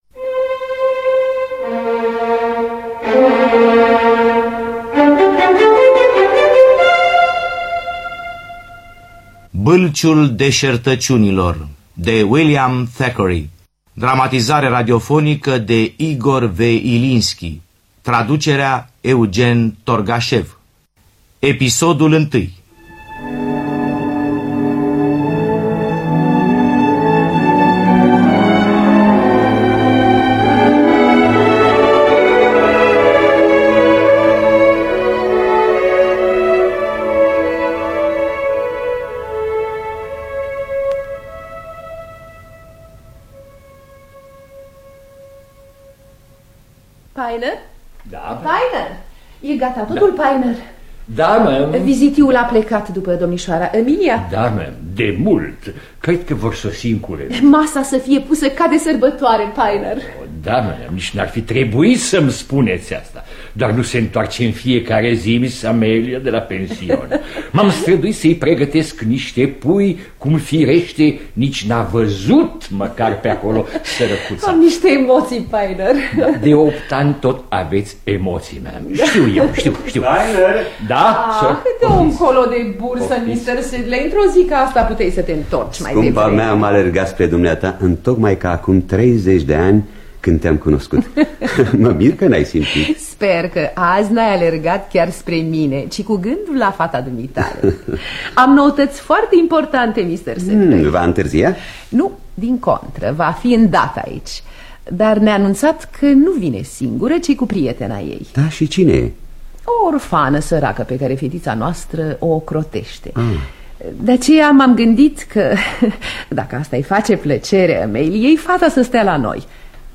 Dramatizarea radiofonică de Igor Vladimirovich Ilinski.